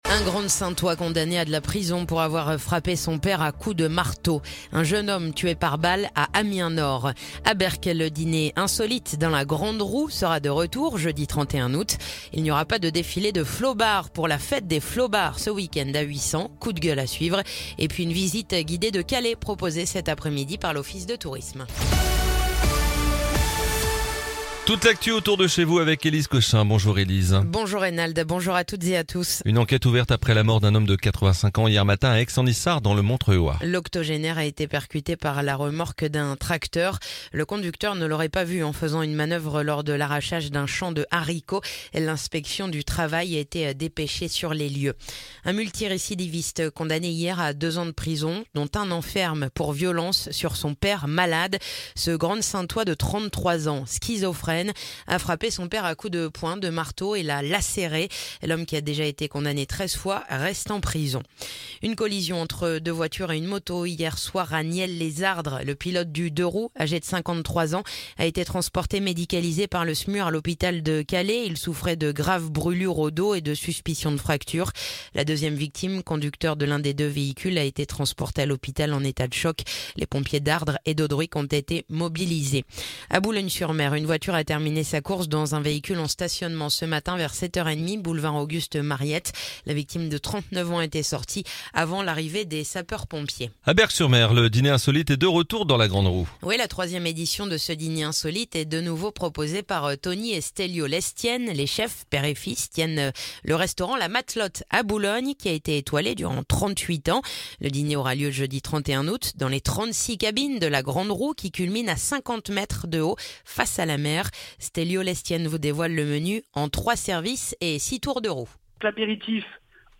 Le journal du mercredi 23 août